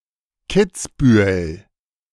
, also: [ˈkɪtsbyːəl]
De-Kitzbühel.ogg.mp3